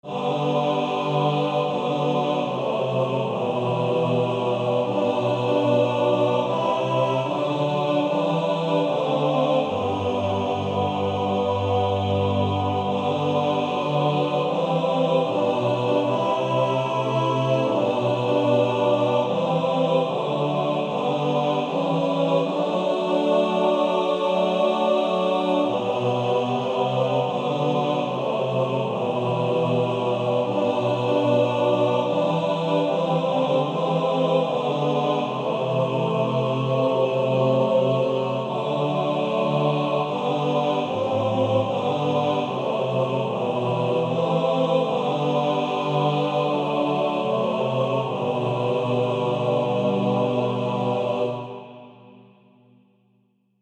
Bass Track.
Practice then with the Chord quietly in the background.